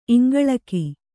♪ iŋgaḷaki